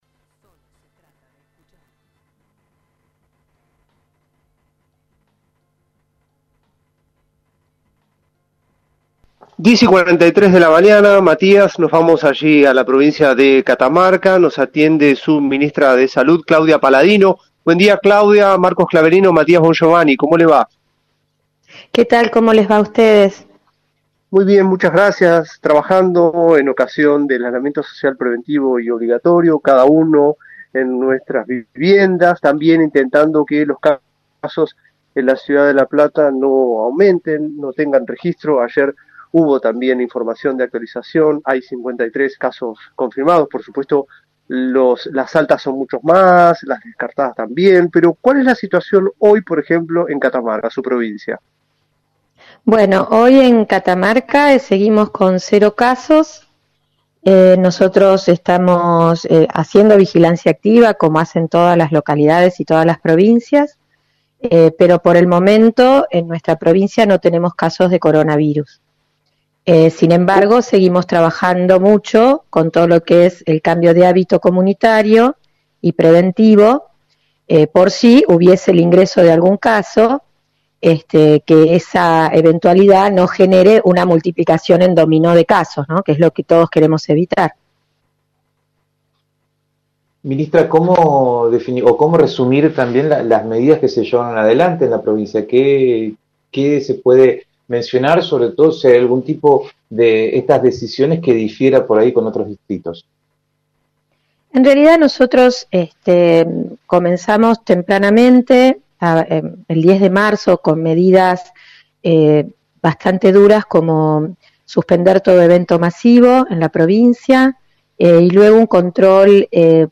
Catamarca sin casos de Covid-19: entrevista a la ministra de Salud – Radio Universidad
Claudia Paladino, ministra de Salud de Catamarca, dialogó con Radio Universidad de La Plata acerca de la situación sanitaria en esa provincia, en relación al Covid-19.